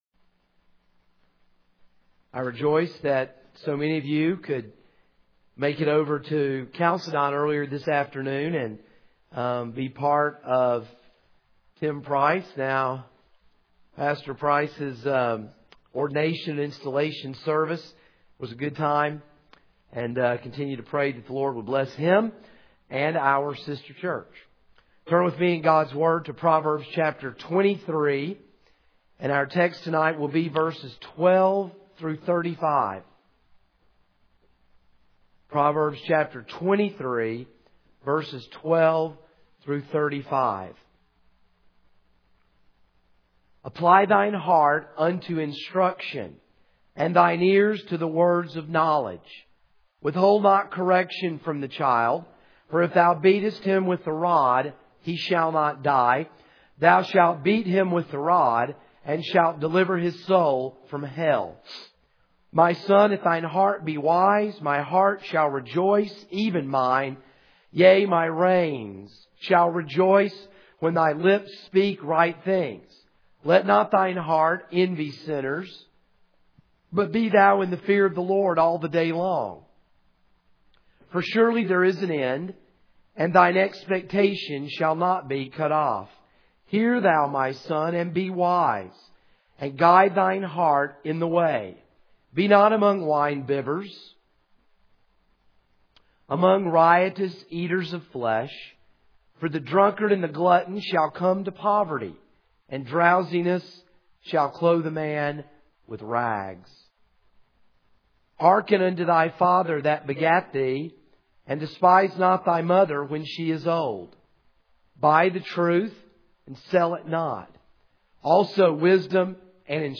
This is a sermon on Proverbs 23:12-35.